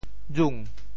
允 部首拼音 部首 儿 总笔划 4 部外笔划 2 普通话 yǔn 潮州发音 潮州 rung2 文 中文解释 允 <形> (会意。